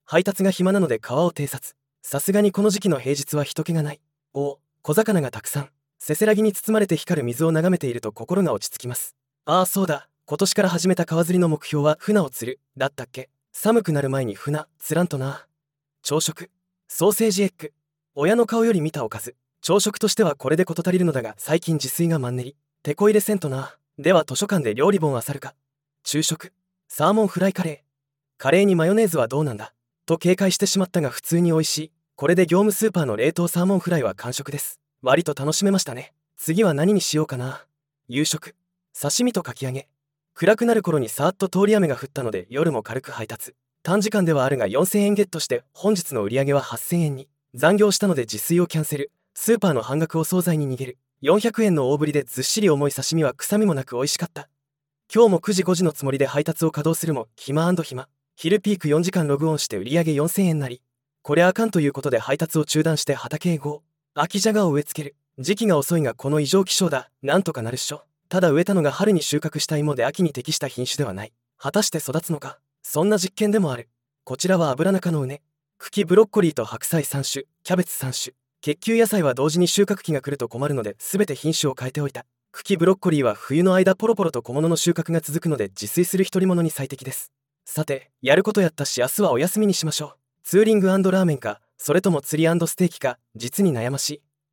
川の様子
せせらぎに包まれて光る水を眺めていると心が落ち着きます。